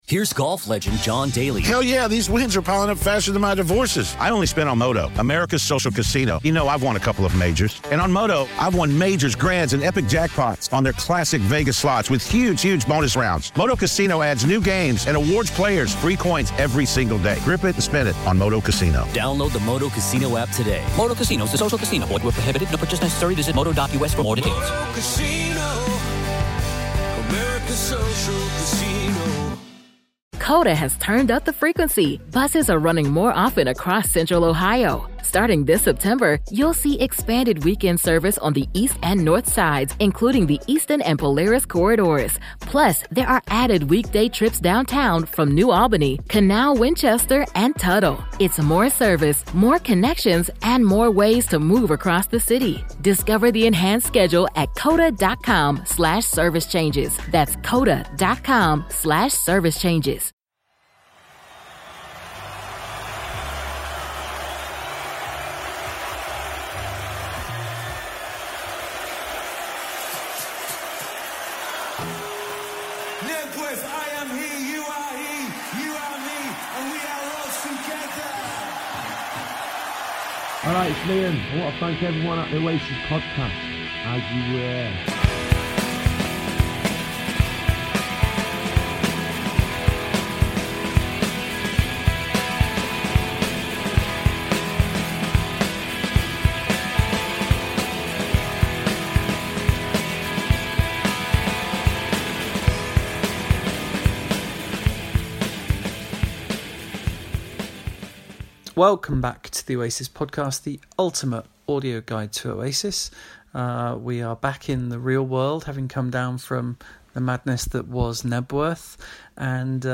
Today is a Patreon call in show with guests